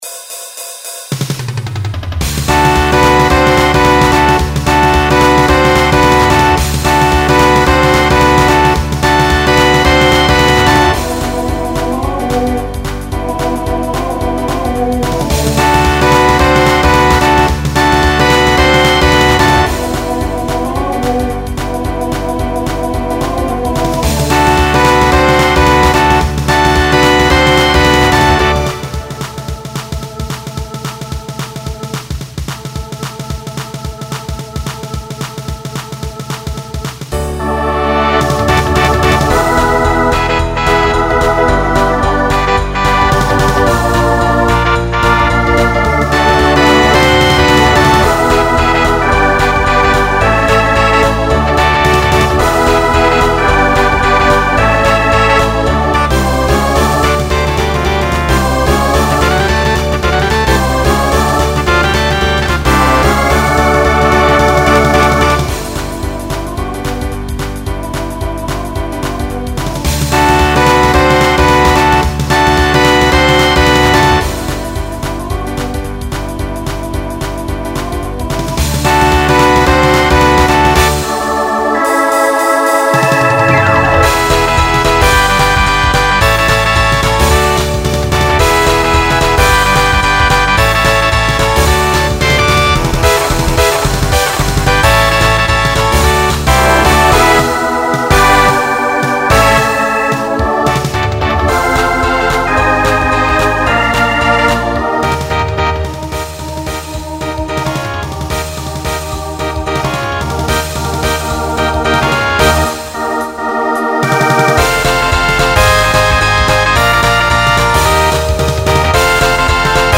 Genre Rock
Voicing SATB